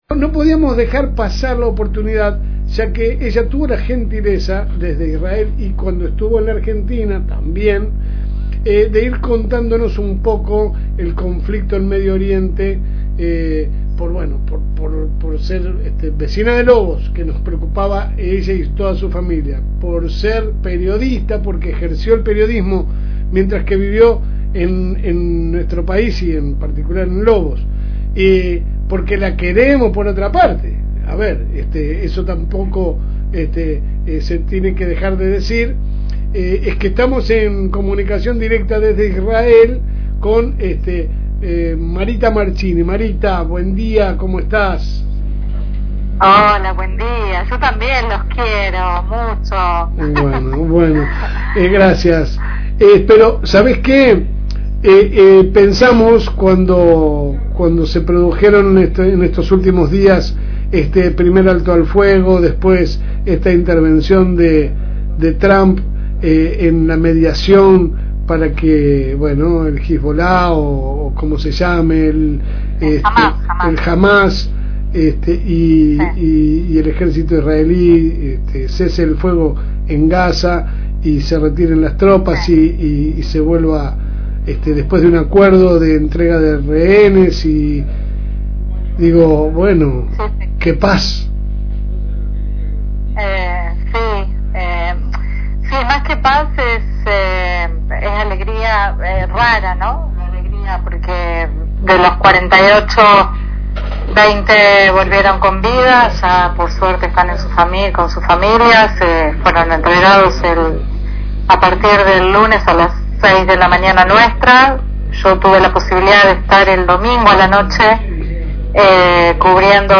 La entrevista exclusiva